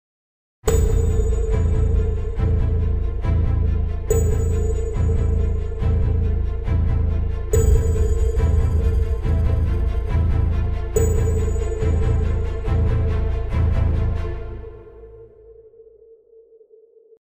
The Starting Composer's Guide To Composing, Orchestrating and Mixing Orchestral Music
Below you can listen to a theme consisting of only a main melody and harmony.